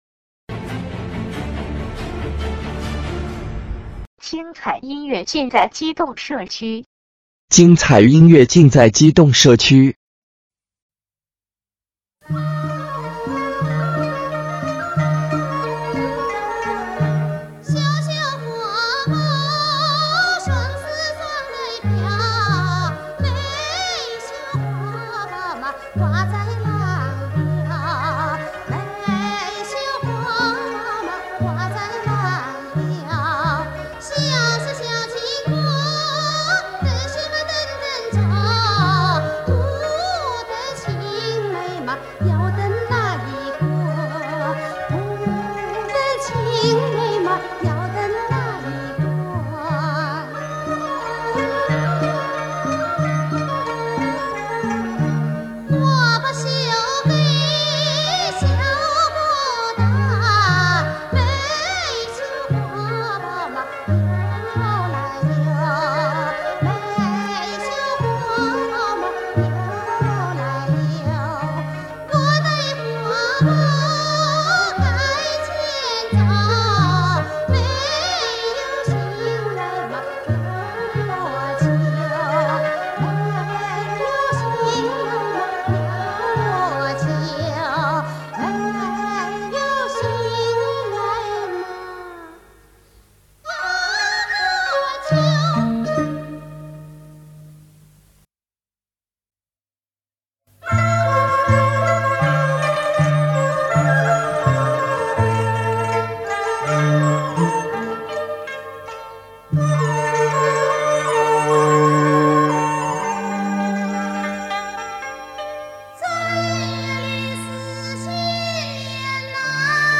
云南民歌